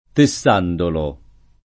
tessandolo [ te SS# ndolo ]